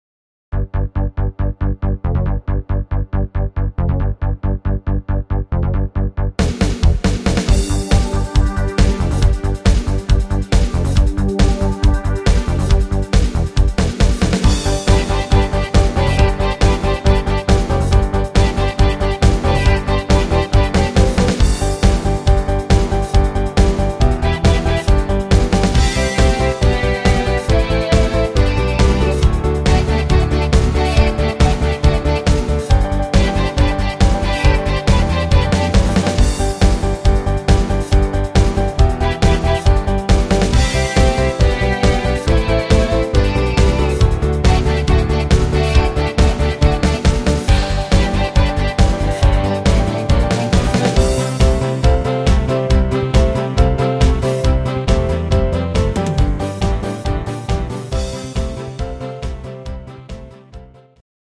Rhythmus  Disco Beat
Art  Pop, Englisch, Oldies